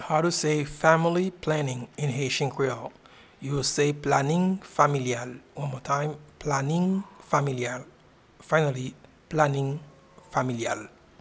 Pronunciation and Transcript:
Family-planning-in-Haitian-Creole-Planin-familyal.mp3